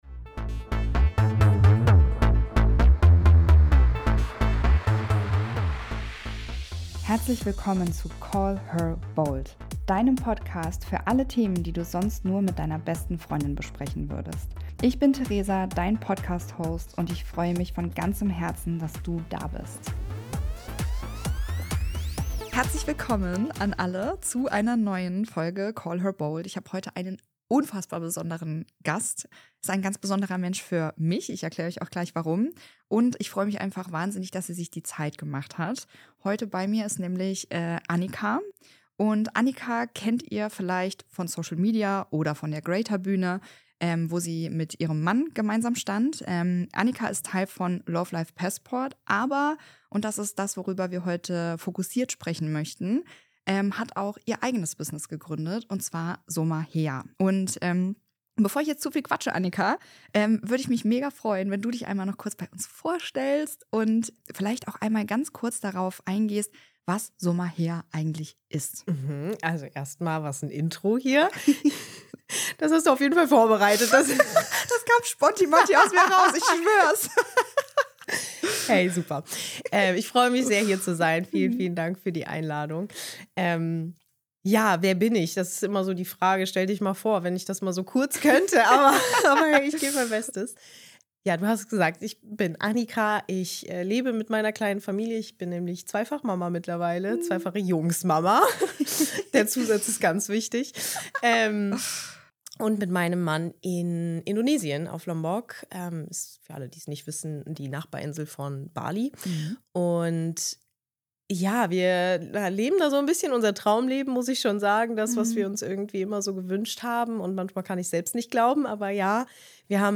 Ein ehrliches Gespräch über Energie, Emotionen und die Kraft, wieder in Verbindung mit sich selbst zu kommen.